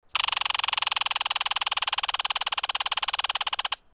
Squirrel:
squirrel.wav